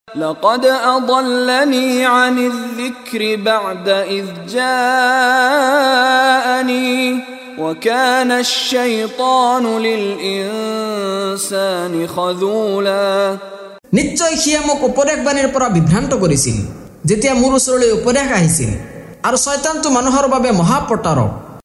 লগতে ক্বাৰী মিশ্বাৰী ৰাশ্বিদ আল-আফাছীৰ কণ্ঠত তিলাৱত।